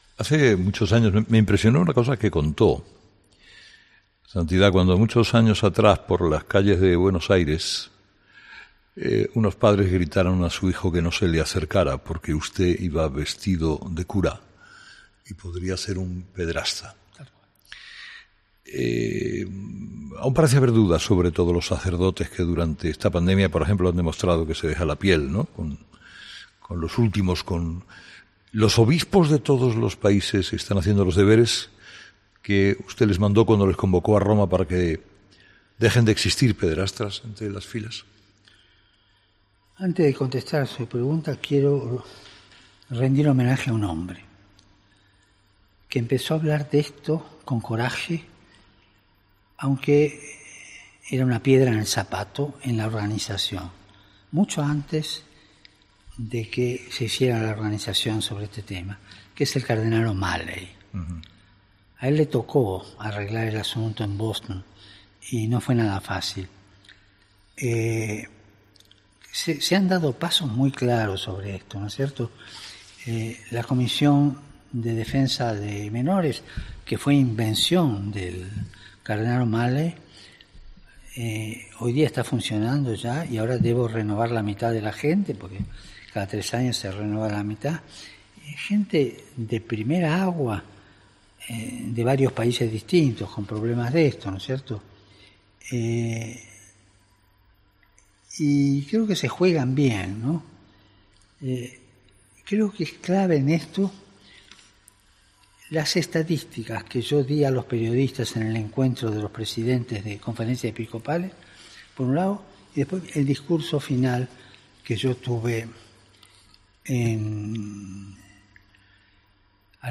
En la entrevista concedida por el Santo Padre a 'Herrera en COPE', ha remarcado que la Comisión de Defensa de Menores, que fue invención del del arzobispo de Boston, el cardenal Seán Patrick O'Malley, “hoy en día está funcionando y ahora debo renovar a la mitad de la gente porque cada tres años se renueva la mitad”, ha precisado el Pontífice.